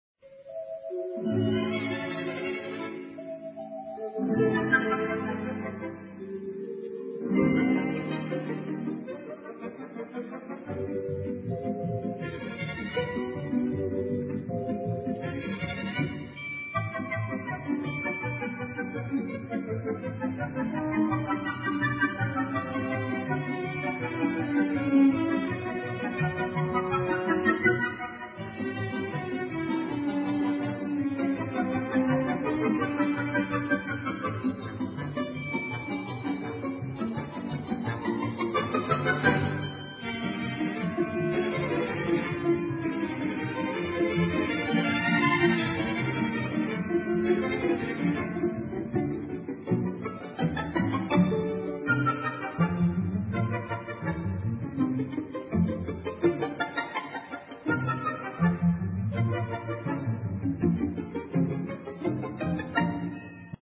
was a breakfast-time light music programme broadcast
Signature Tune